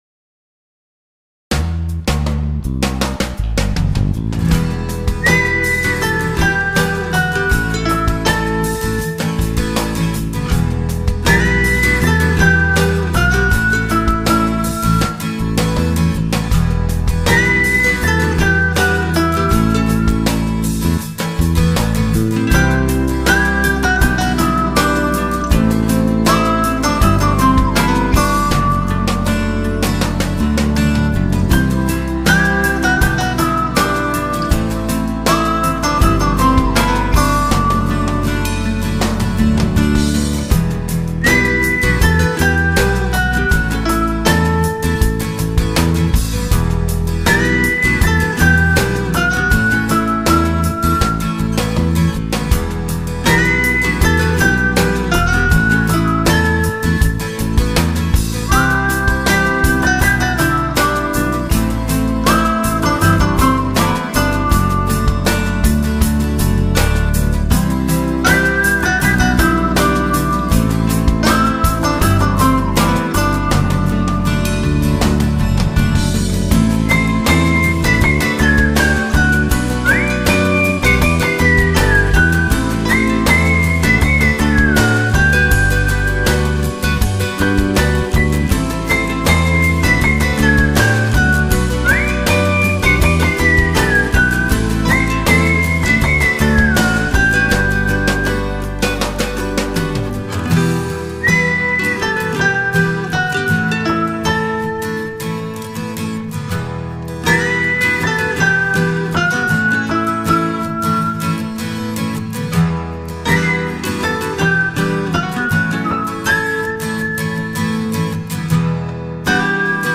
ıslık
tema dizi müziği, mutlu huzurlu rahatlatıcı fon müziği.